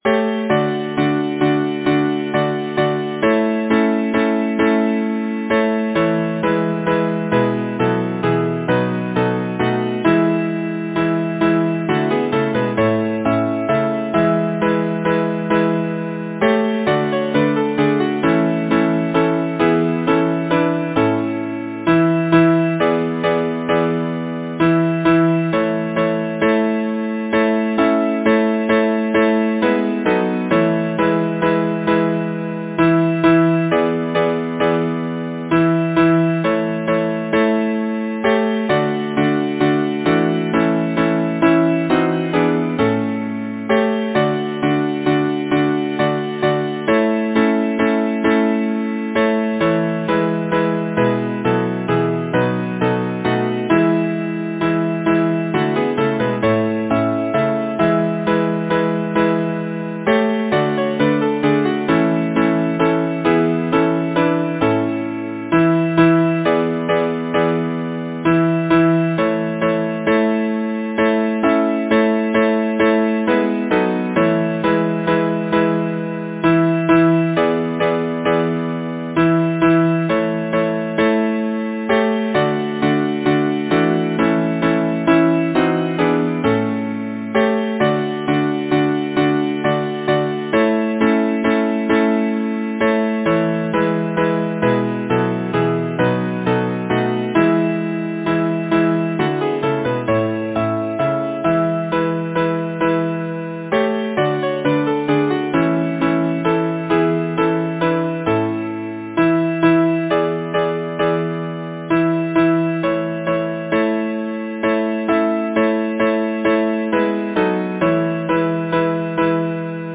Title: Pay as You Go Composer: John Franklin Fargo Lyricist: Number of voices: 4vv Voicing: SATB Genre: Secular, Partsong
Language: English Instruments: A cappella